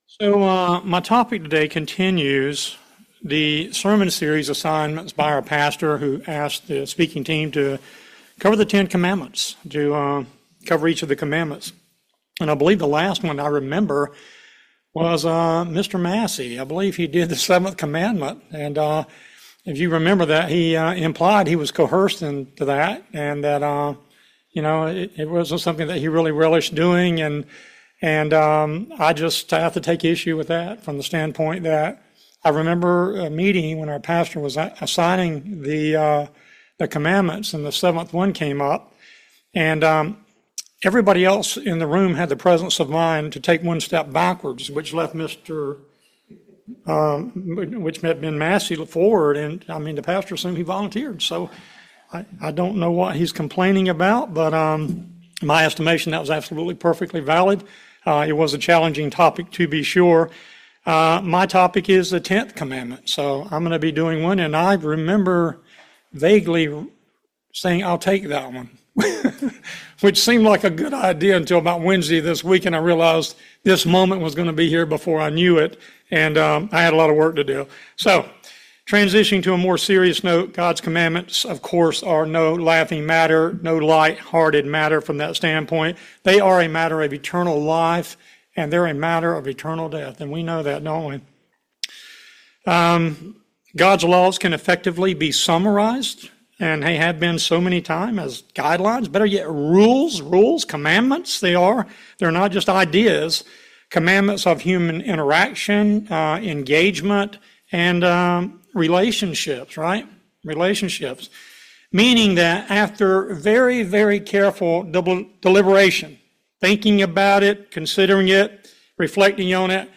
This sermon reviews in detail the 10th Commandment, in terms of definition, instructions and warnings, as well as antidote and solution to overcome covetousness.